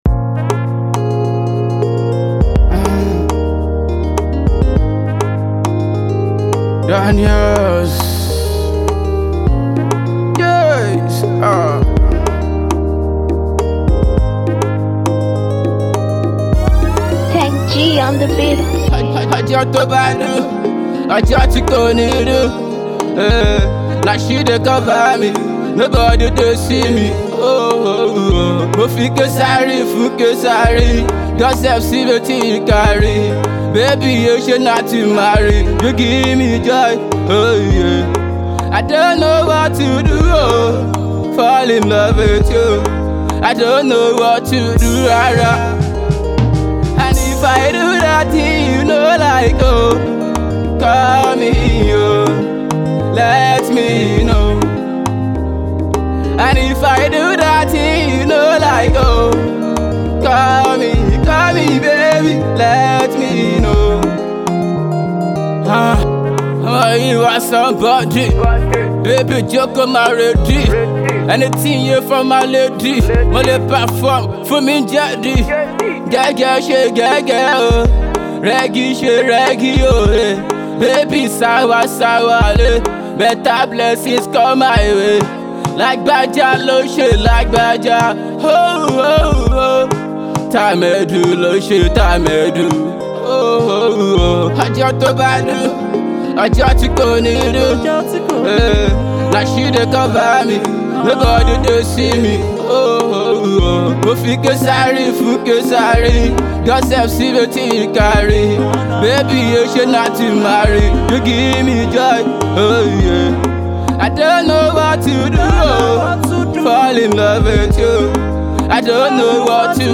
a love song